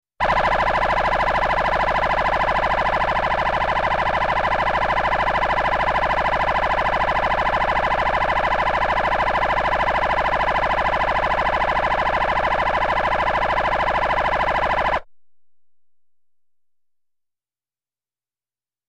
Phaser | Sneak On The Lot
Phaser Siren; Close Perspective.